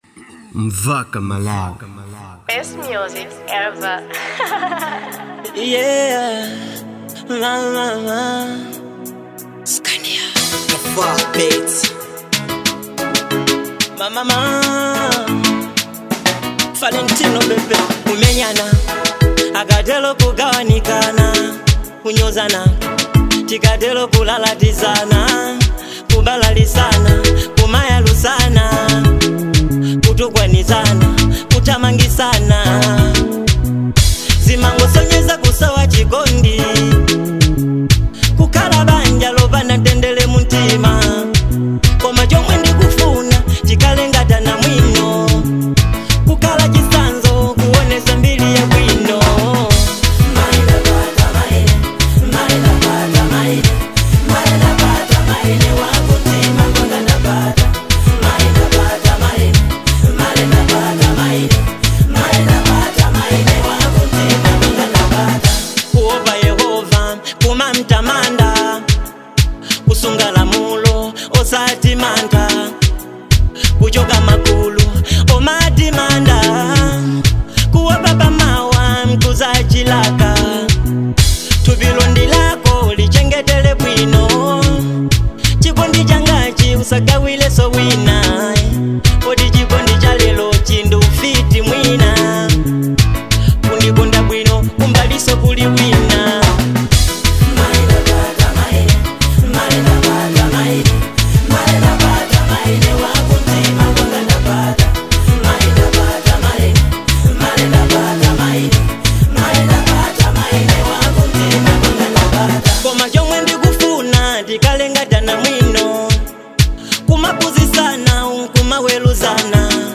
type: love song